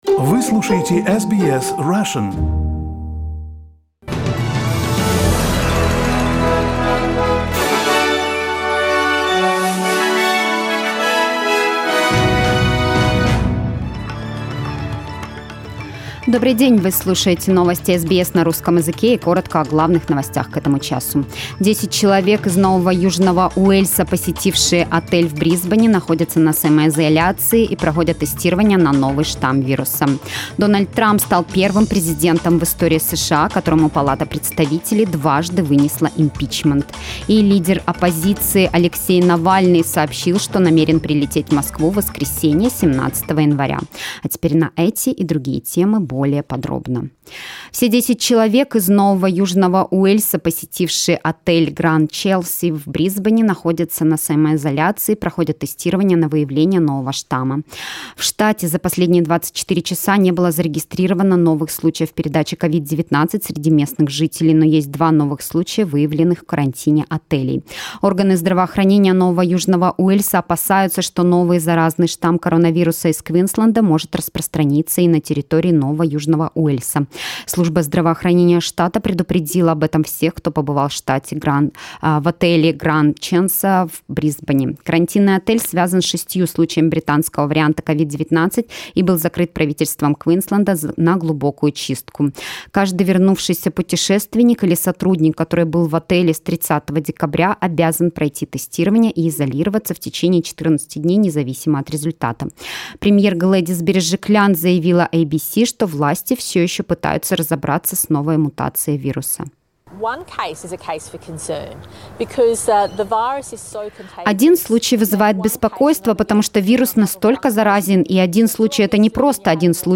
Новостной выпуск за 14 декабря